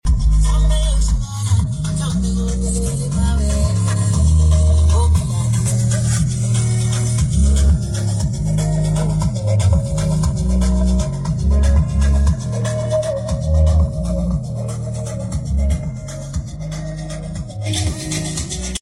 R6500 2 X BULLET TWEETERS 2 X MIDRANGE 7600WATTS AMPLIFIER 12" SUBWOOFER 12" PORTED ENCLOSURE 1 X CUSTOM BACKBOARD 1 X WIRING KIT 2 X SPLITTERS 1 X CONVERTER + INSTALLATION